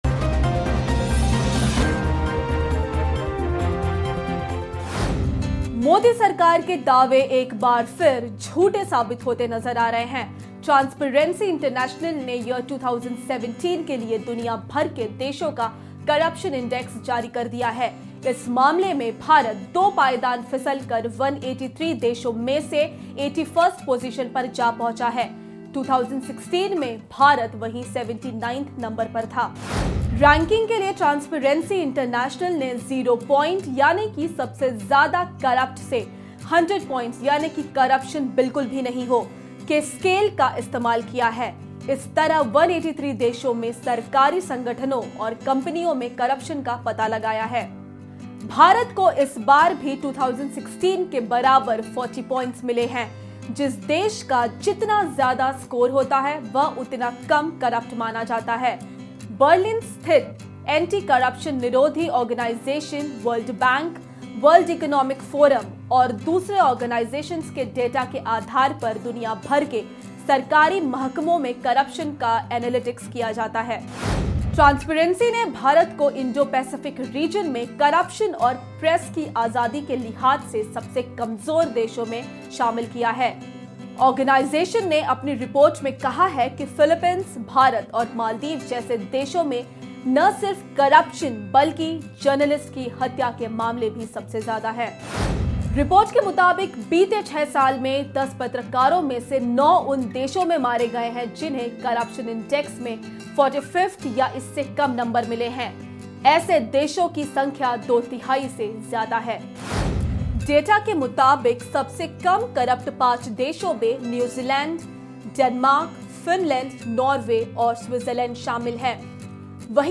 News Report / ट्रांसपेरेंसी इंटरनेशनल 2017: पहले से ज्यादा भ्रष्ट साबित हुआ भारत, एक साल में 2 पायदान फिसला